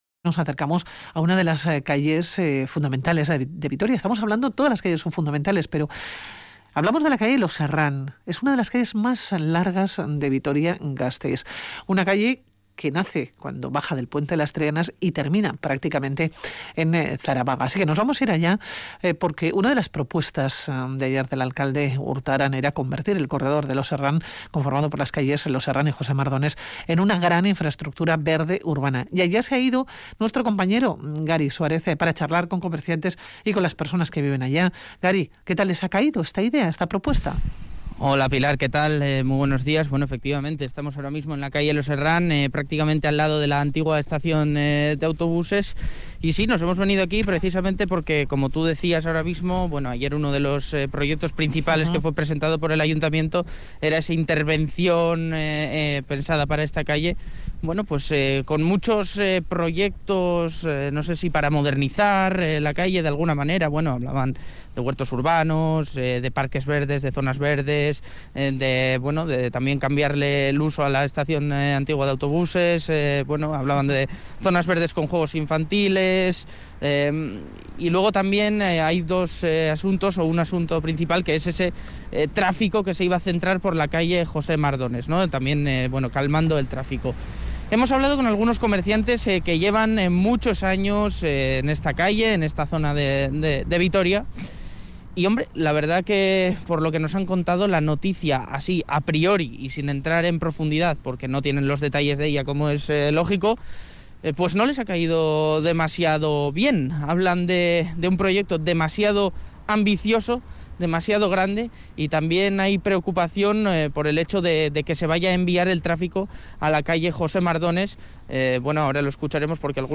Audio: Nos acercamos hasta la calle Los Herrán y preguntamos a los comerciantes qué les parece este proyecto que cambiaría por completo el aspecto de la zona.